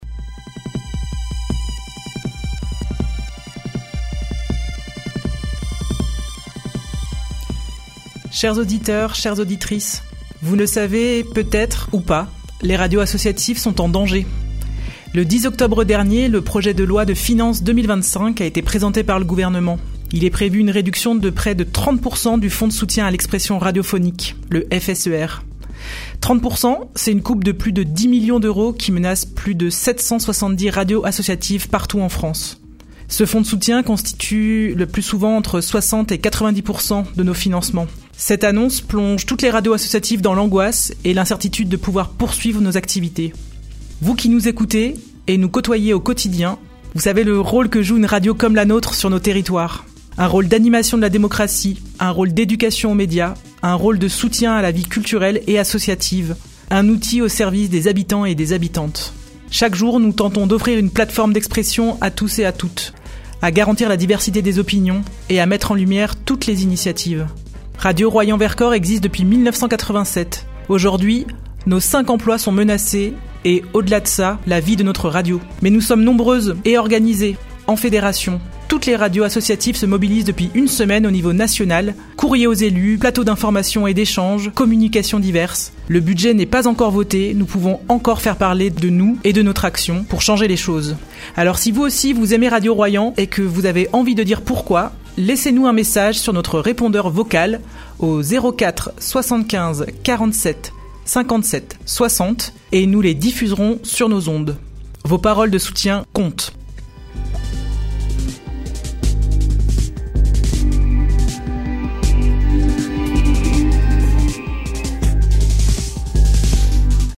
Radio BLV, radio consoeur située à Bourg les Valence, a réalisé une émission en direct sur la menace qui pèsent sur les radios associatives.